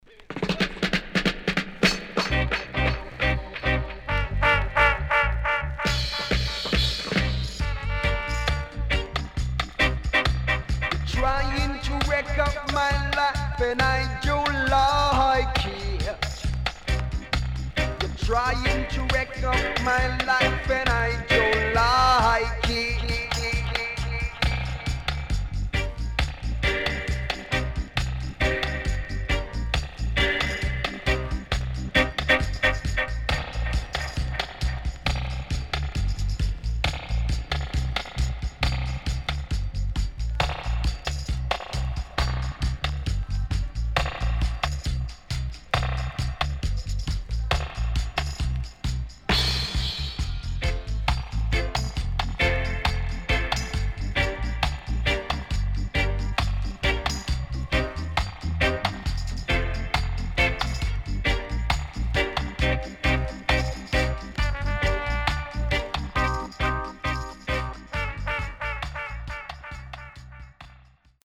HOME > REGGAE / ROOTS  >  STEPPER
SIDE A:所々チリノイズがあり、少しプチノイズ入ります。